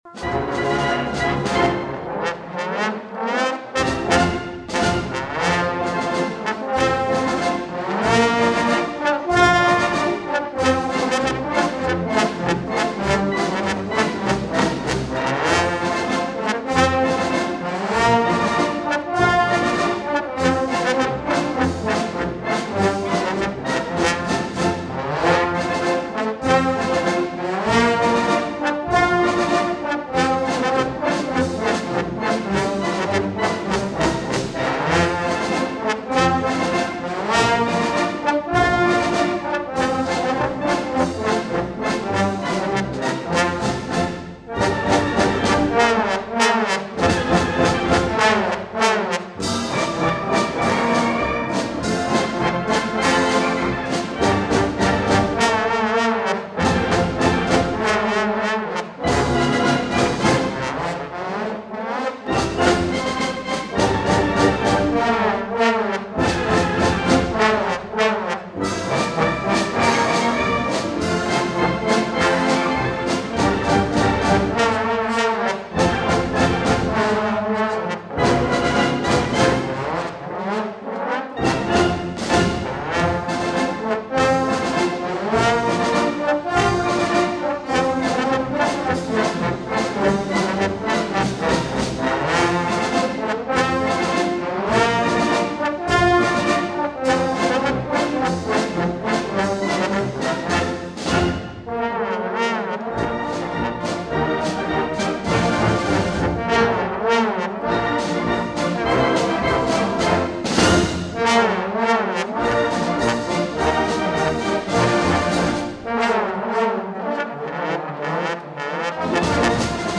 Concerts were held on that Friday night at Waynesboro Senior High School and Saturday afternoon at Gettysburg Senior High School.
A recording of the concerts was professional made and CDs produced.